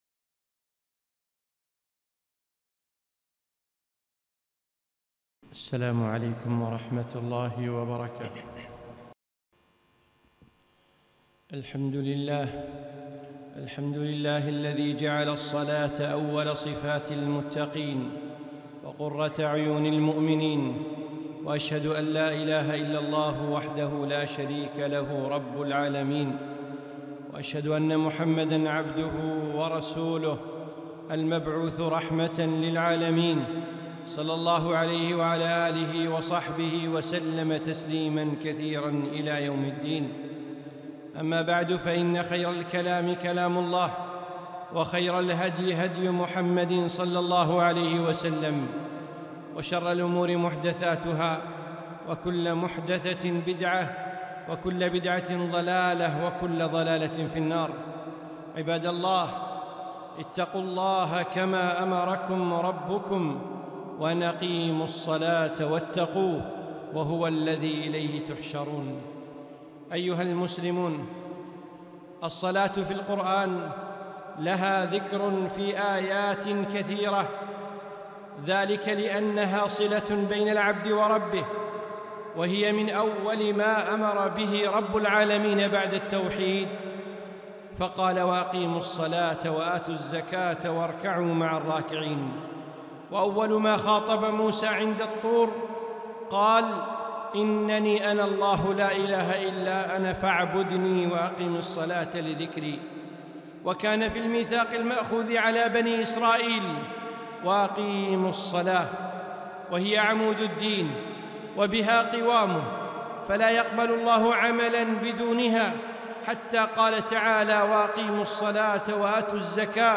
خطبة - الصلاة في القرآن 9-6-1442